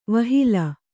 ♪ vahila